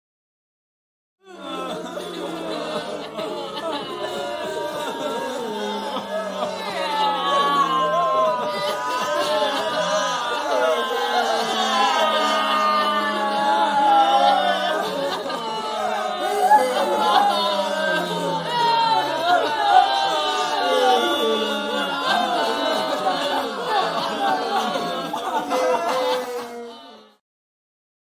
دانلود آهنگ گریه جمعیت از افکت صوتی انسان و موجودات زنده
دانلود صدای گریه جمعیت از ساعد نیوز با لینک مستقیم و کیفیت بالا
جلوه های صوتی